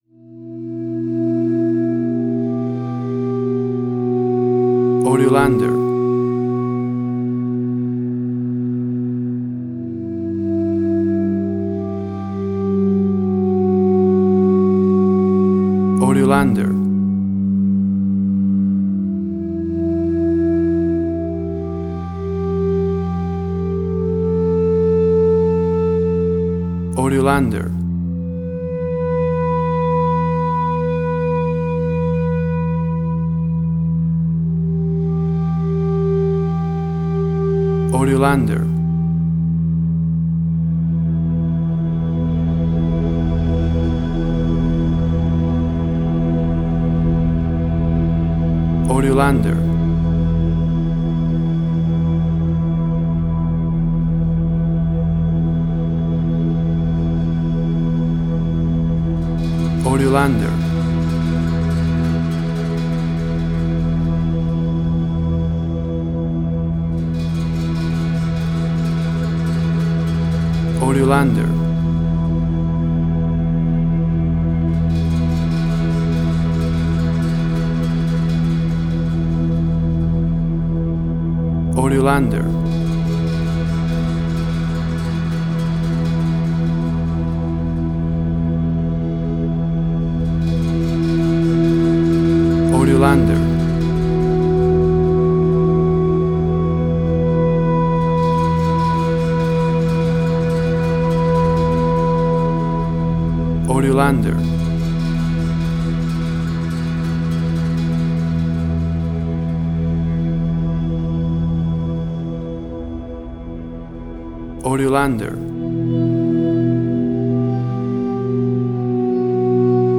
Soundscapes
ambients, fx sounds